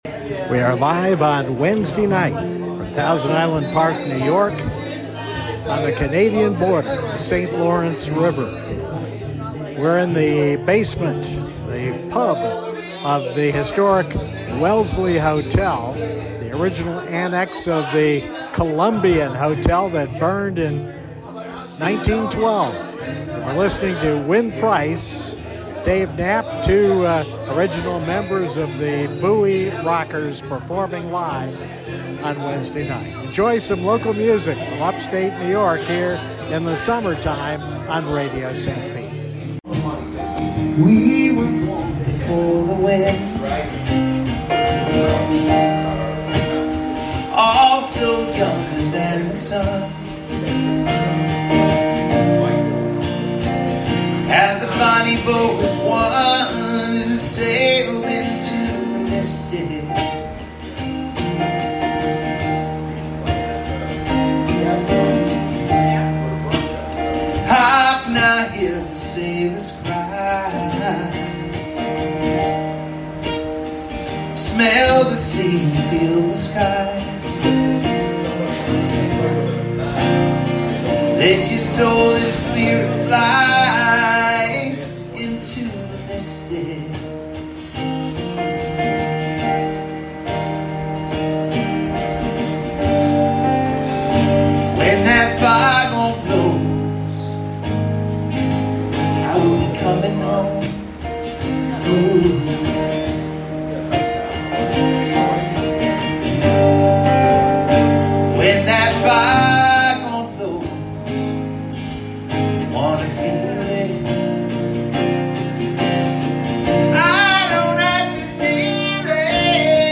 We were live from the 100 year old Welsley Hotel on Thousand Island Park, NY